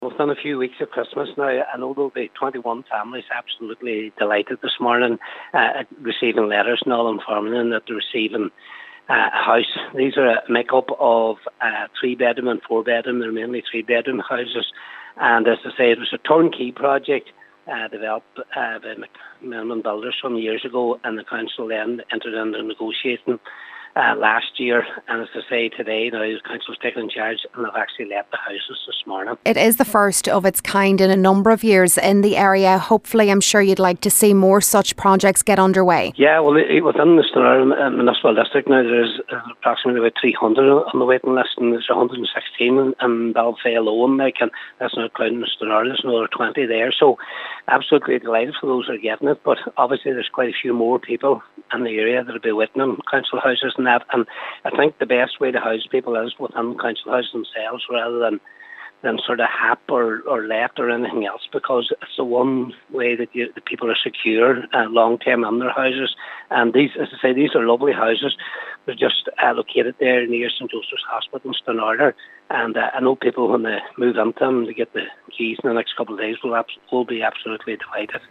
Cathaoirleach of the Stranorlar Municipal District Cllr Patrick McGowan says this will help to ease the local housing waiting list considerably: